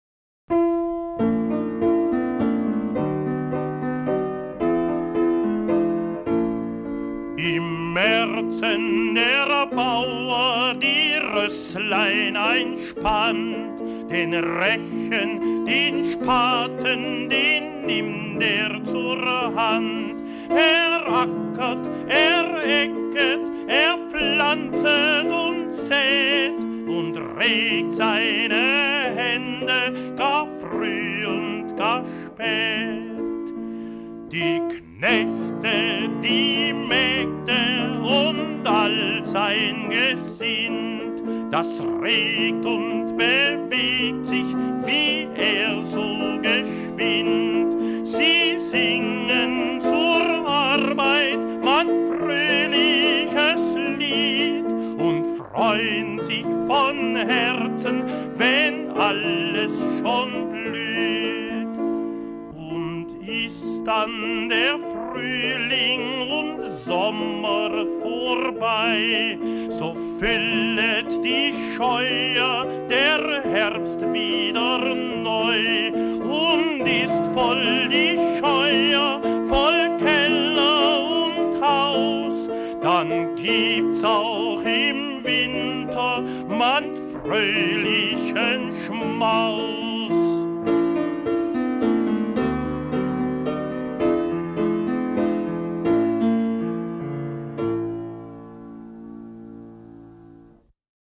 deutschsprachiges Volks- und Kinderlied